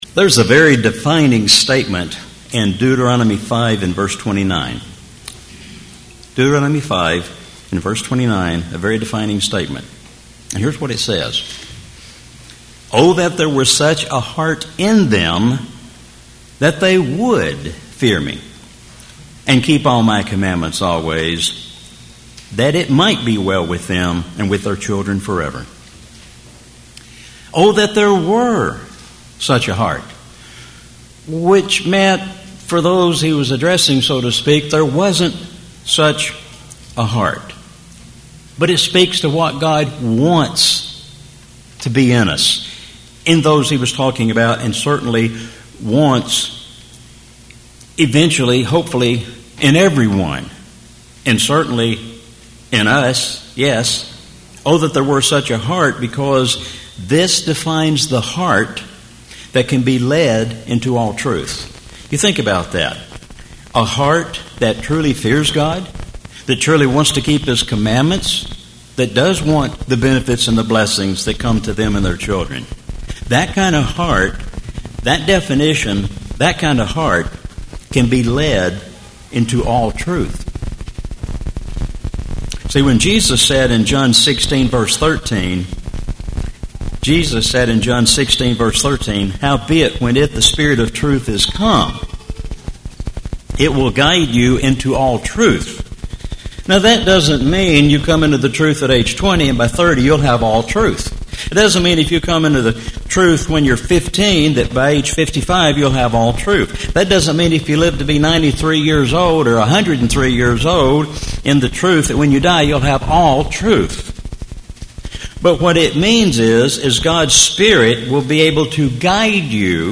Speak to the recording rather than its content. Please bear with us during the first 6 minutes of the recording. The technical difficulties will be resolved.